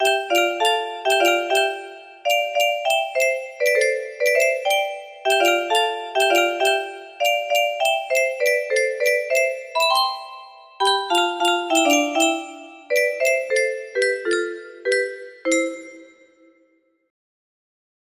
Shortened + sped-up chorus for 104 length